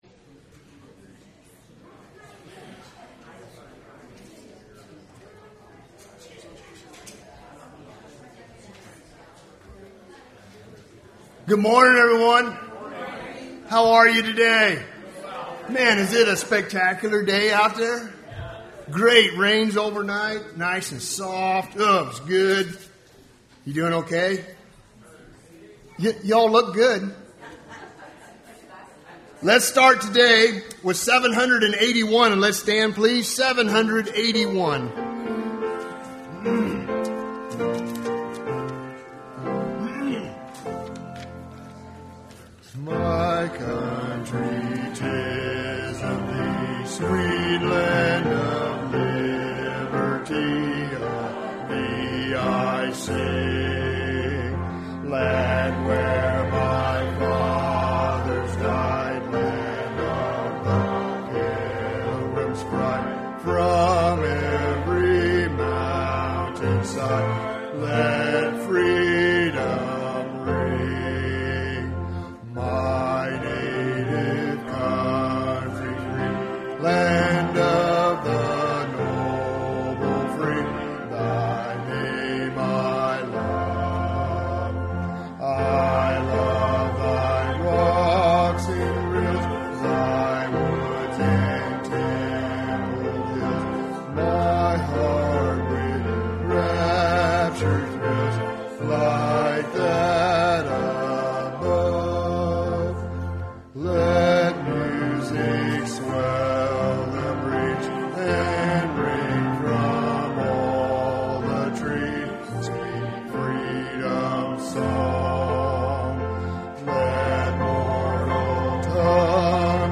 Passage: Matthew 24 Service Type: Sunday Morning Service Topics